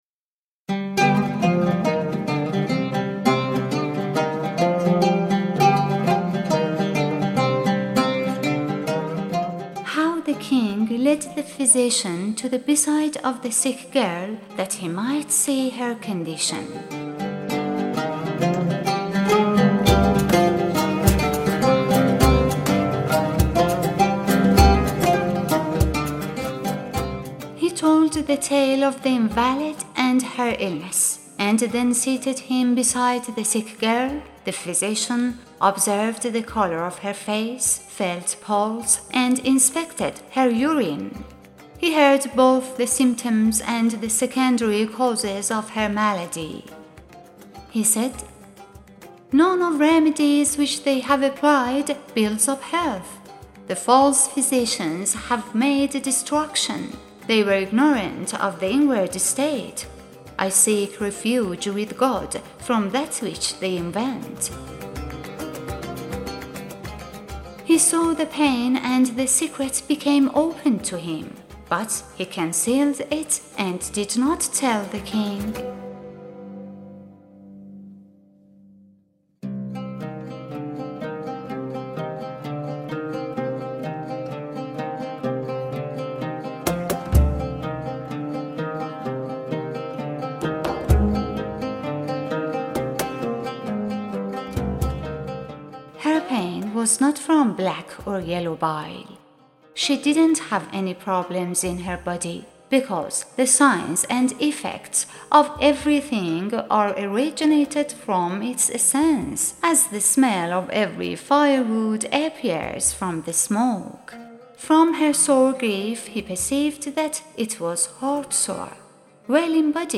Music by: Adnan Joubran, Samir Joubran, Wissam Joubran